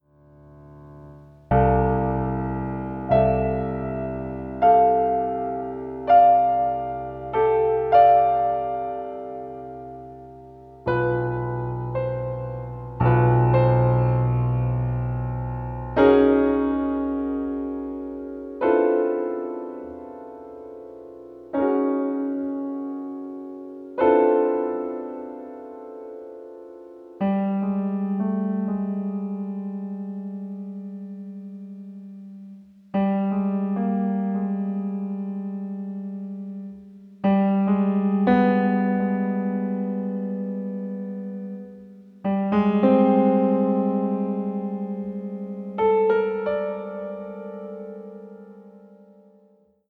solo album